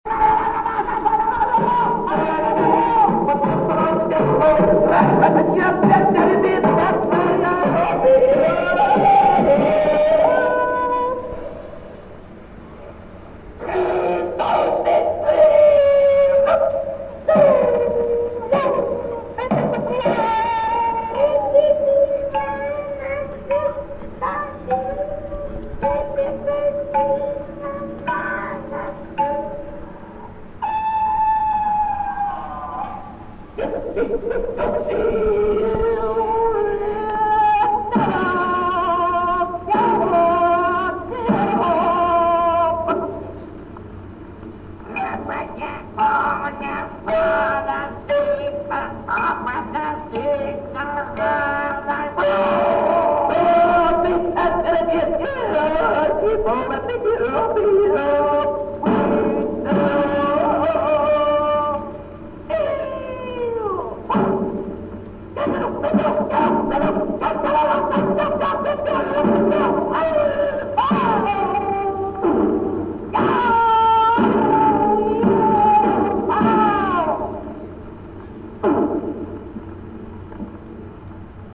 La révolution silencieuse est en marche: le dernier commando antibruit a �t� perp�tr� en marge du vernissage de l'expo Artaud de la BNF, lundi 7 novembre de 18h a 19h.
pu r�sonner entre les quatre tours qui enserrent l'esplanade glaciale